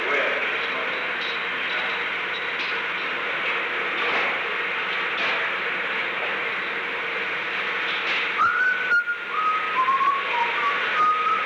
Participants: United States Secret Service agents Recording Device: Oval Office
The Oval Office taping system captured this recording, which is known as Conversation 660-014 of the White House Tapes.
[Unintelligible] The unknown people left at an unknown time before 11:59 pm. 37 NIXON PRESIDENTIAL MATERIALS STAFF Tape Subject Log (rev. 10/06) Conv.